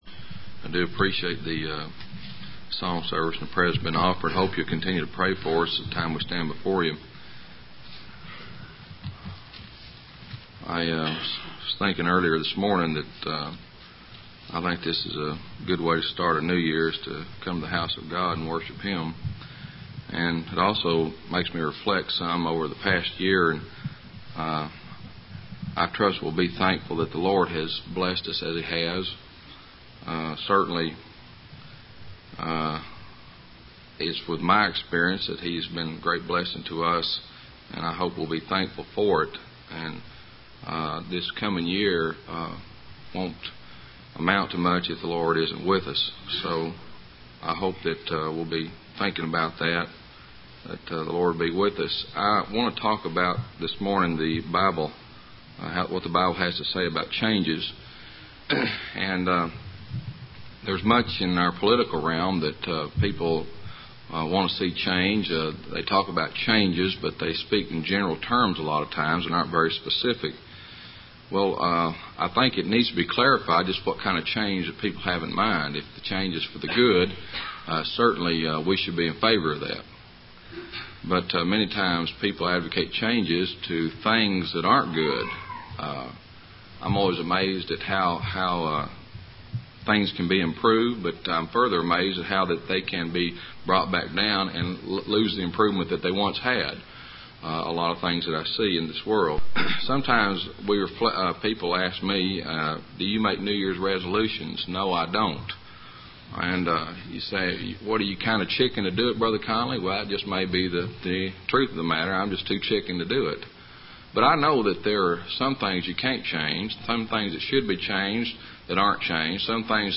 Middleton Creek PBC (MS) %todo_render% « The Last Will and Testament